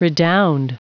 Prononciation du mot redound en anglais (fichier audio)
Prononciation du mot : redound